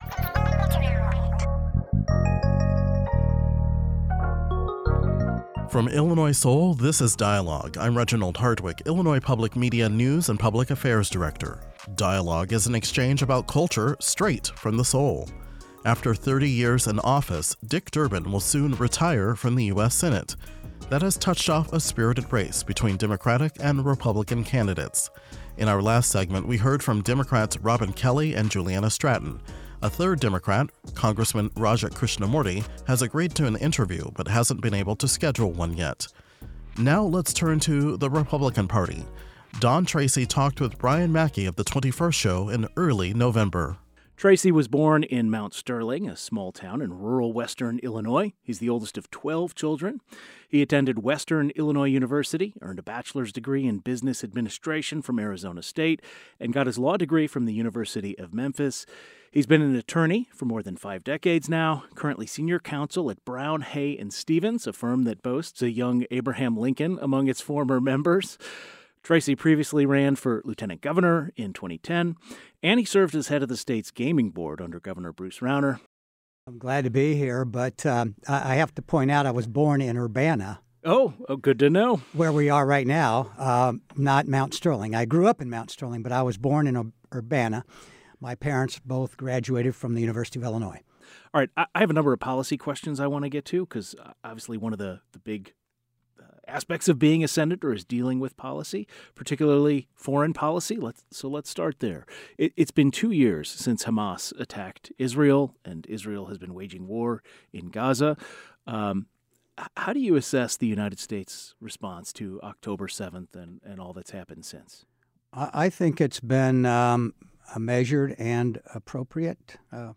He sat down with the 21st Show in November. Senator Dick Durbin announced earlier this year he would not be seeking reelection.